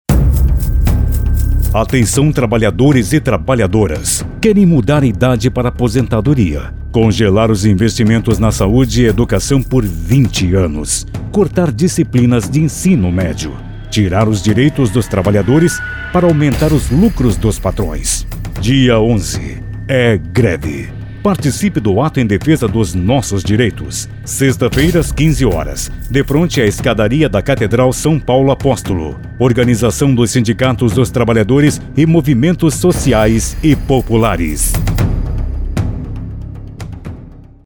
Inserção de rádio convoca população a participar de ato em Blumenau